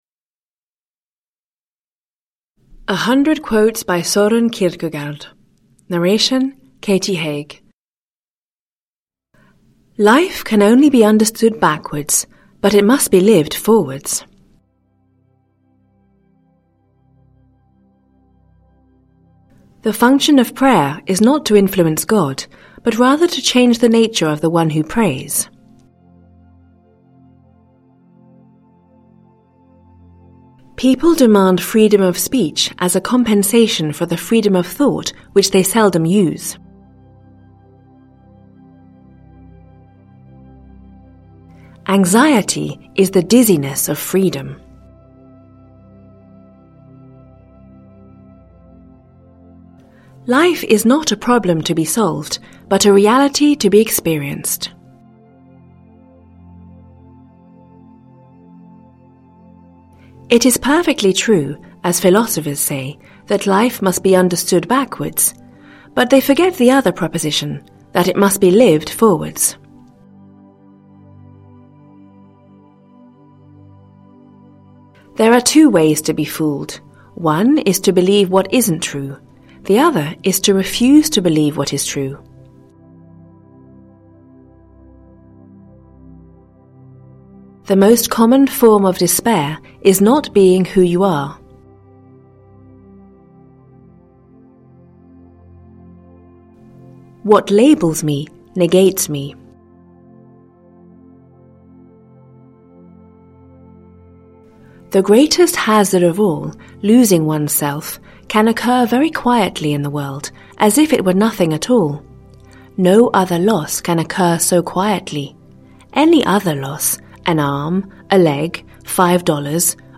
Learn Strength with Existential Philosophers: Nietzsche & Kierkegaard – Ljudbok